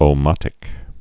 (ō-mŏtĭk)